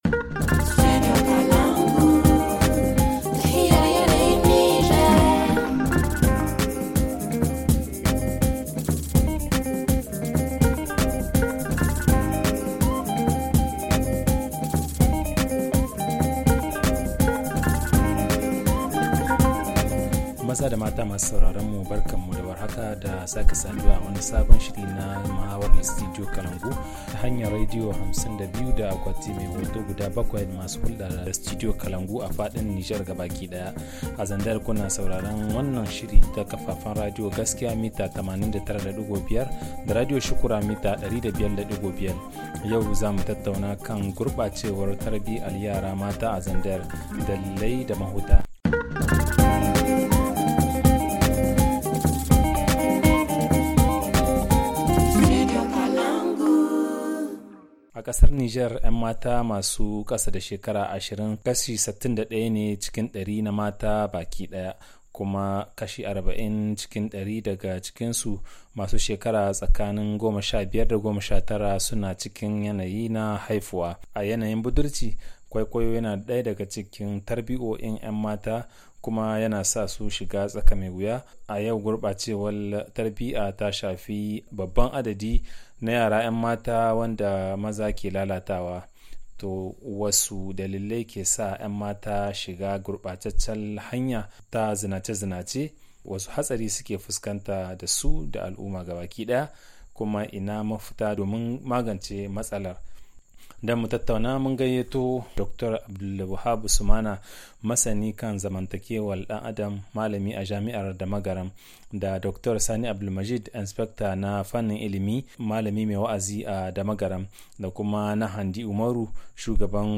Le forum en haoussa